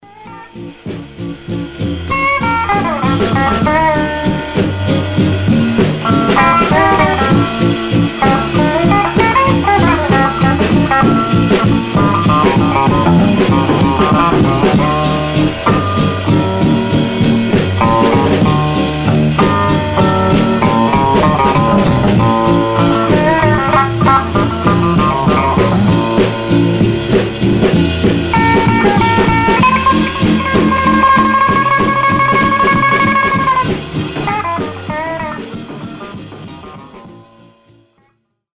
Some surface noise/wear Stereo/mono Mono
R&B Instrumental